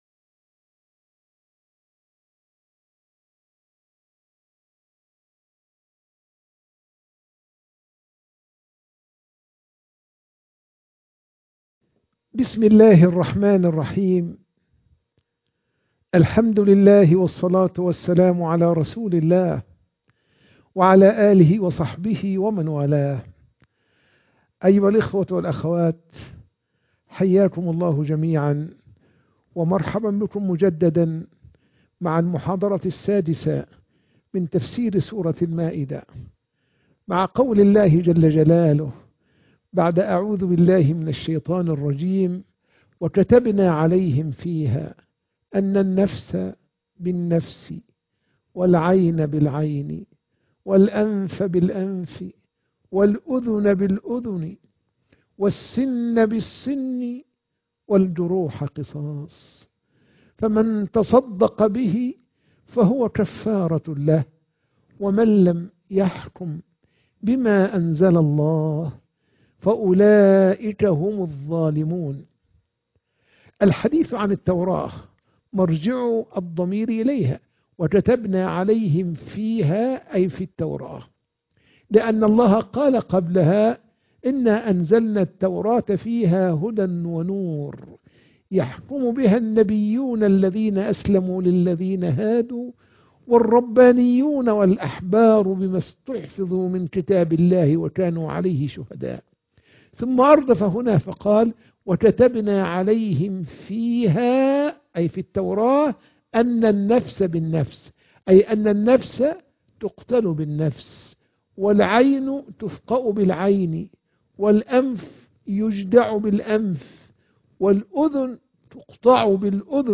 الَّذِينَ آمَنُوا وَلَمْ يَلْبِسُوا إِيمَانَهُم بِظُلْمٍ أُولَٰئِكَ لَهُمُ الْأَمْنُ - بث مباشر